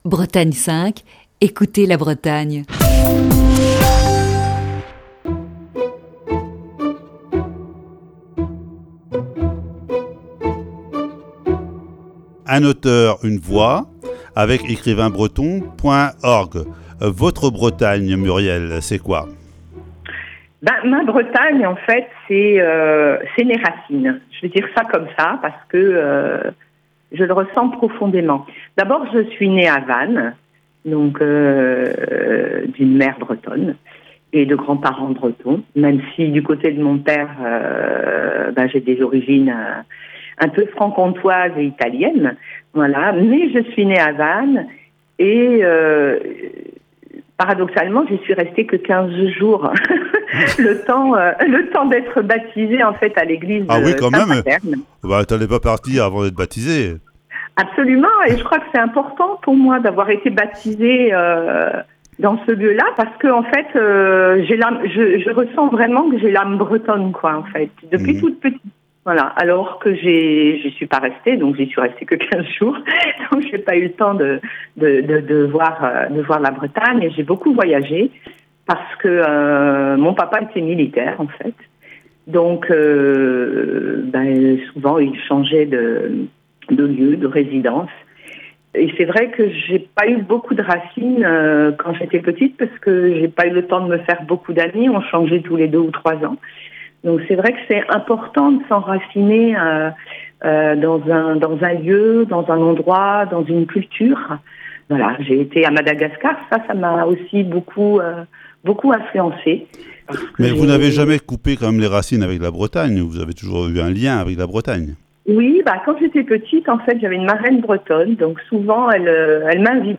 Ce matin, deuxième partie de cet entretien.